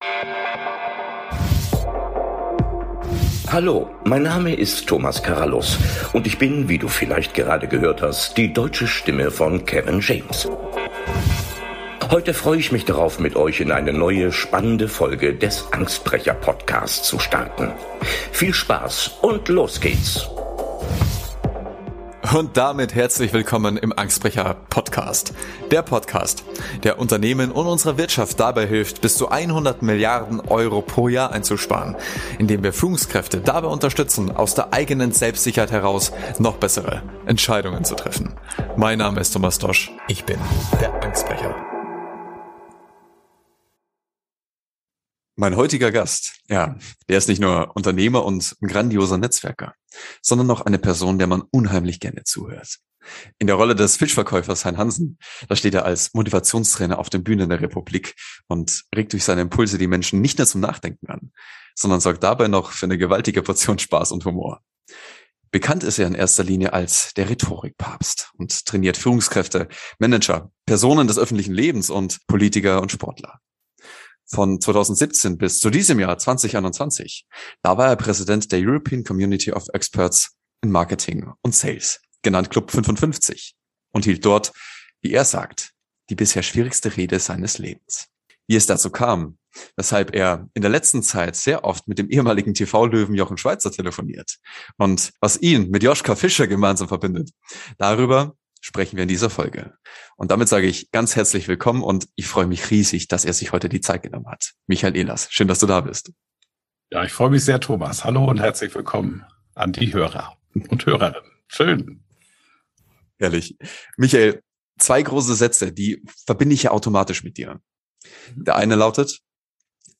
Freut euch auf das Interview mit dem Rhetorik- Papst